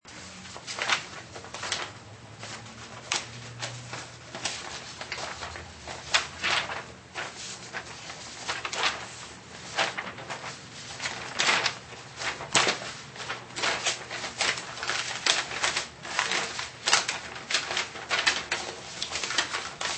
PASANDO HOJAS CON LLUVIA DE FONDO PASSING SHEETS
Ambient sound effects
Pasando_hojas_con_lluvia_de_fondo_Passing_sheets.mp3